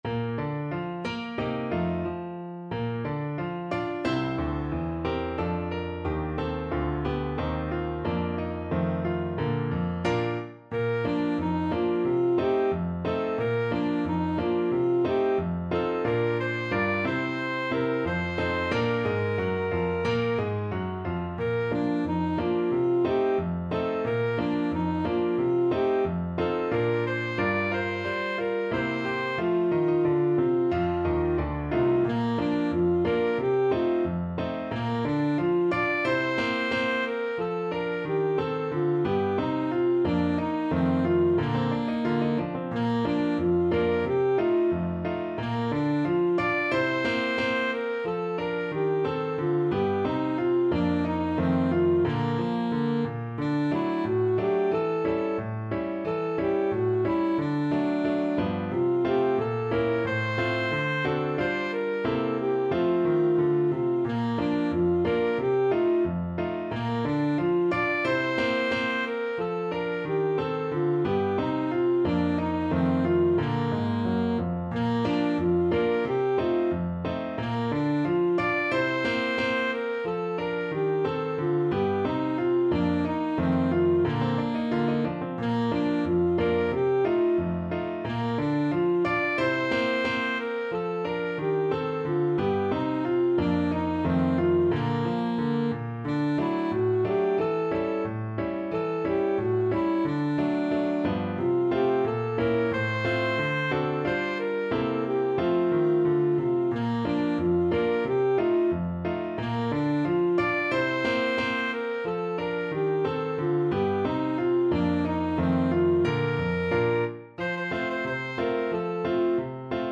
Alto Saxophone
2/2 (View more 2/2 Music)
=90 Fast and cheerful
Pop (View more Pop Saxophone Music)